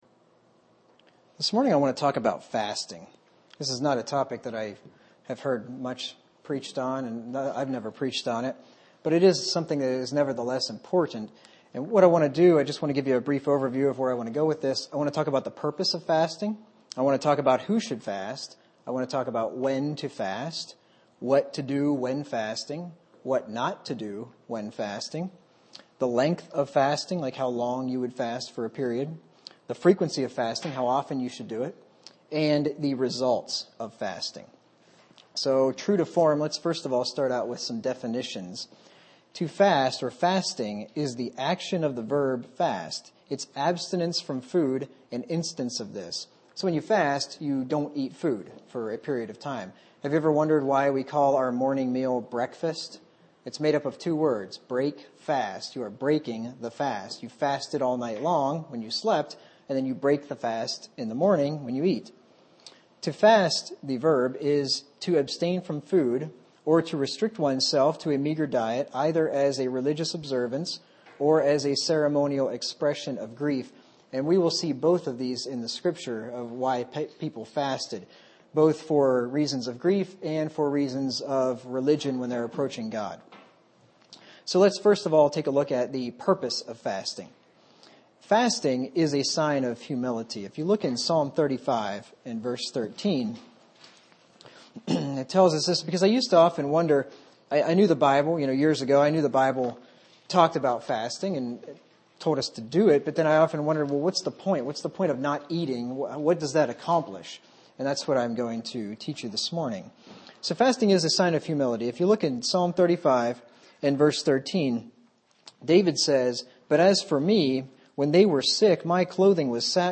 Format: MP3 Mono 22 kHz 30 Kbps (ABR)